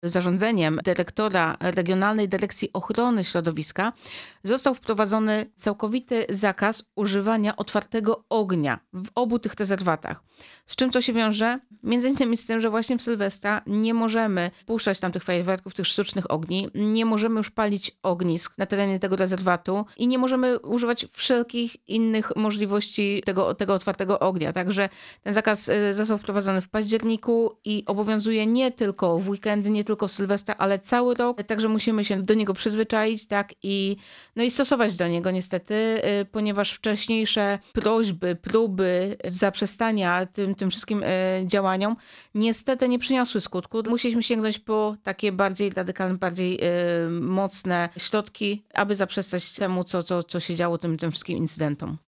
Zbliżający się Sylwester, rosnąca popularność górskich wędrówek, potrzeba ochrony przyrody oraz 5. edycja akcji „Choinka dla Życia” – to główne tematy rozmowy w studiu Radia Rodzina.